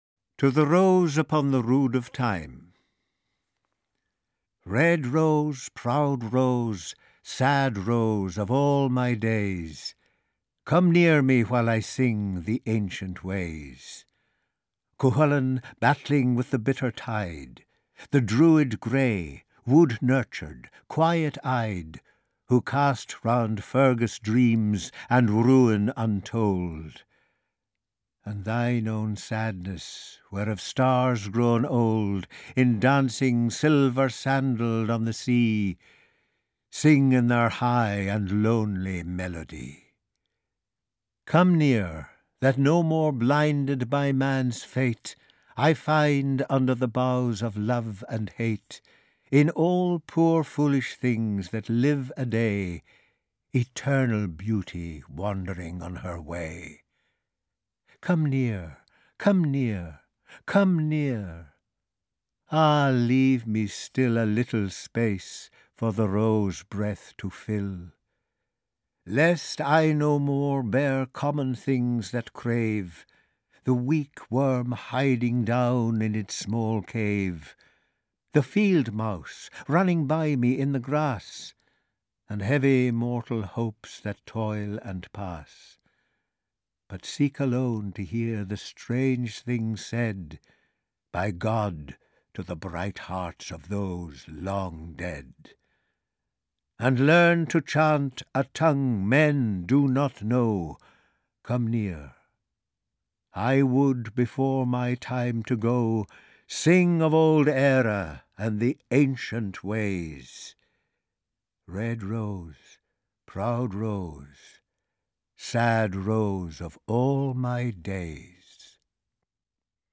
Ukázka z knihy
He left a large legacy of outstanding poems, and the finest are collected here: Down by the Salley Gardens, The Lake Isle of Inisfree, The Secret Rose and He Wishes for the Cloths of Heaven. They are read by a strong cast led by Olivier award winner Jim Norton.